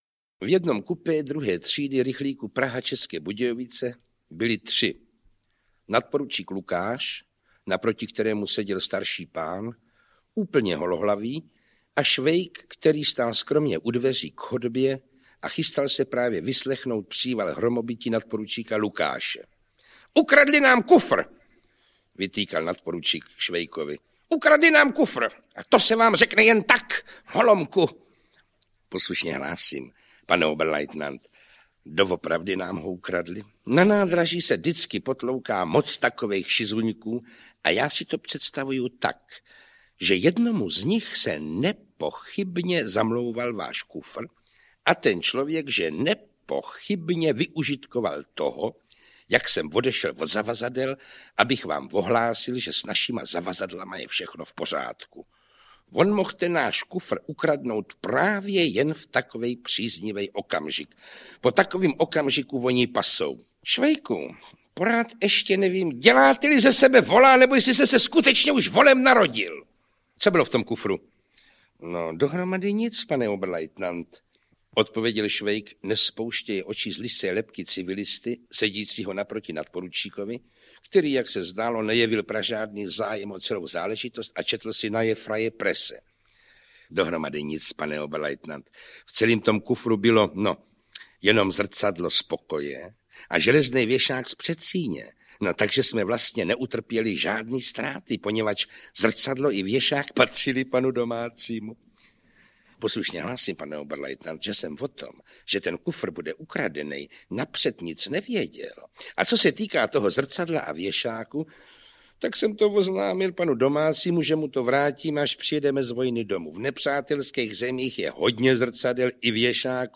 Pokračování četby světově proslulého románu Jaroslava Haška v interpretaci nejpopulárnější, v podání Jana Wericha.
Audio kniha
Ukázka z knihy
Desítky postav, se kterými se Švejk na vojně setkává, Werich interpretuje množstvím barevných odstínů hlasu, podtrhuje jejich životnost a zvyšuje tak účinek neobyčejného humoru, jímž je celý román nabit.